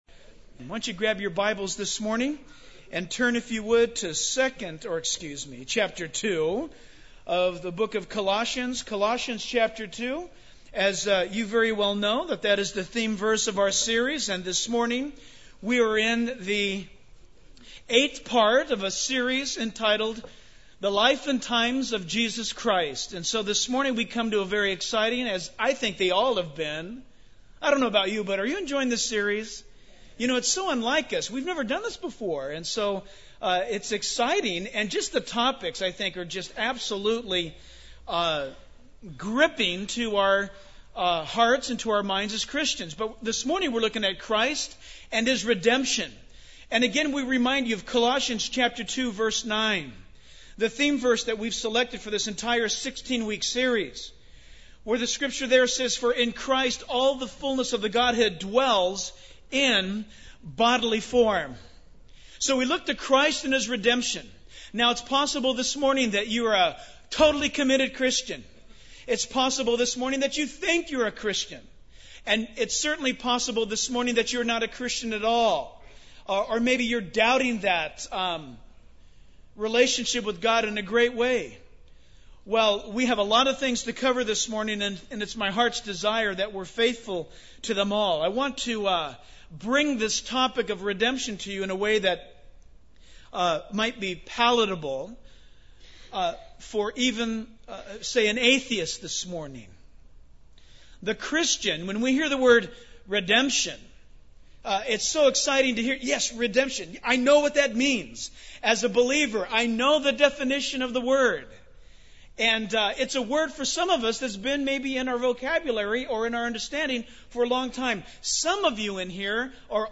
In this sermon, the preacher emphasizes the need for redemption for every individual. He explains that man's attempt to redeem himself is futile and that only through God's grace and the sacrifice of Jesus Christ can true redemption be achieved.